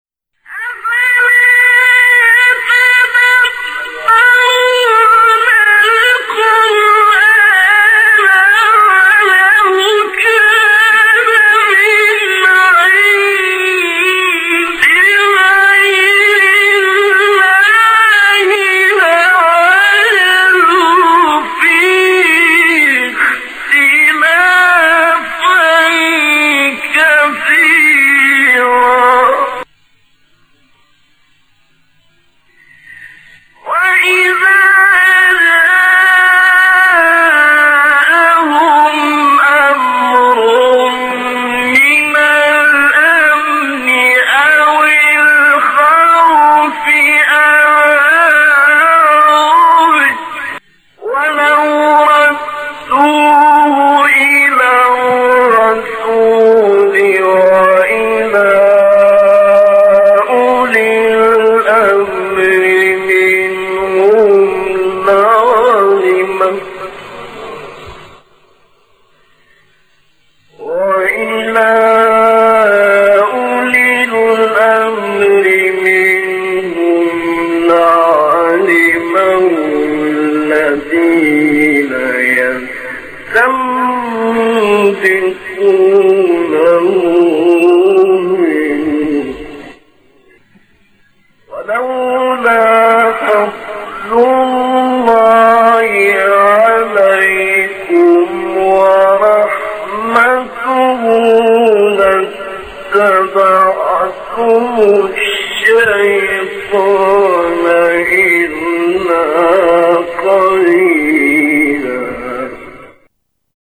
من هو هذا القارئ :
يقلد مصطفى إسماعيل كثيرا في أسلوبه ..
.. وأظنها على مقام النهاوند ..